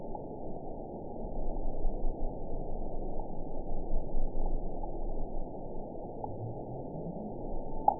event 912544 date 03/29/22 time 02:45:49 GMT (3 years, 1 month ago) score 8.85 location TSS-AB03 detected by nrw target species NRW annotations +NRW Spectrogram: Frequency (kHz) vs. Time (s) audio not available .wav